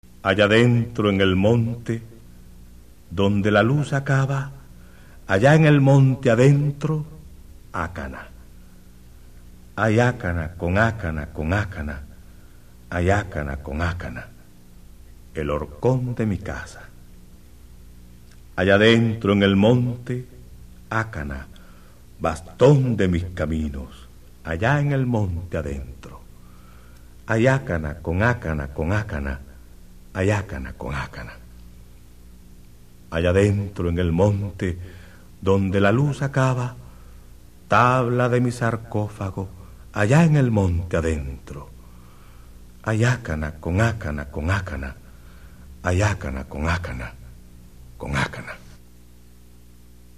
¡Escute estas poesías na voz do autor!